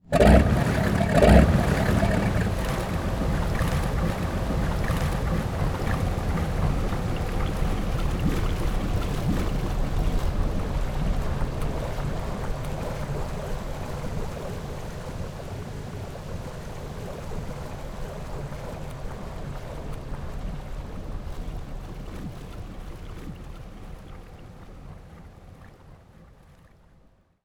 boatstart.wav